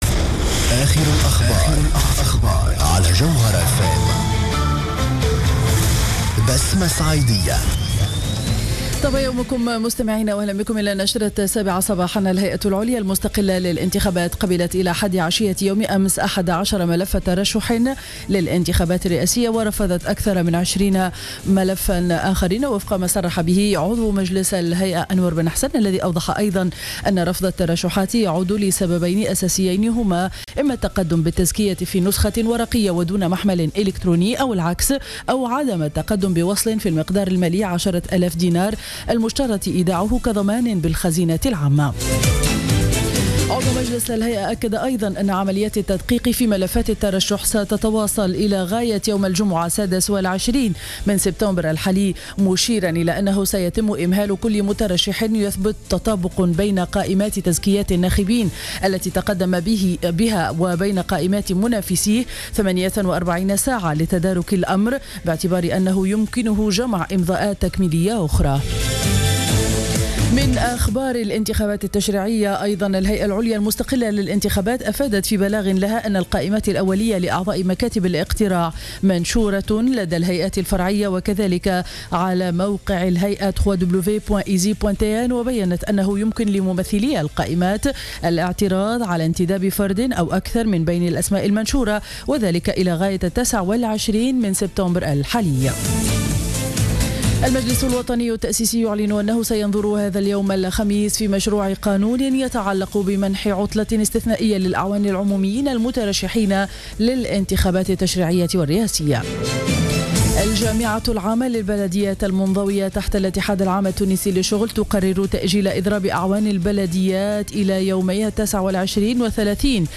نشرة أخبار السابعة صباحا ليوم الخميس 25-09-14